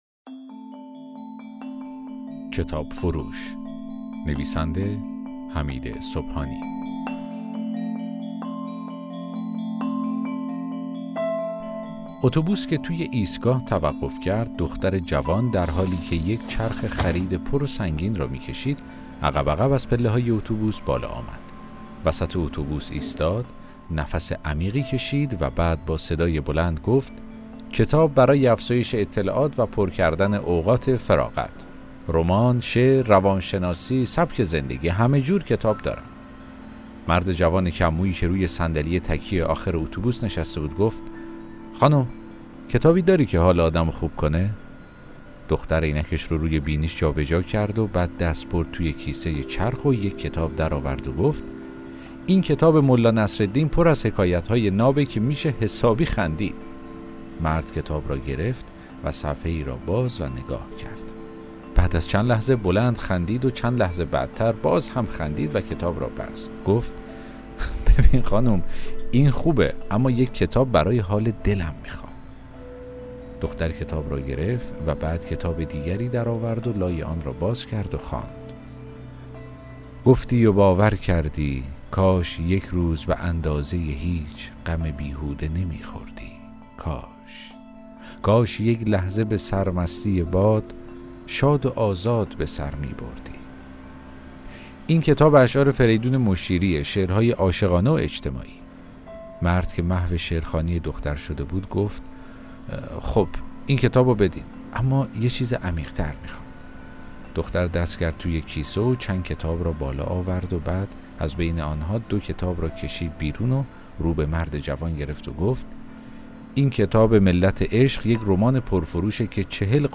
داستان صوتی: کتاب فروش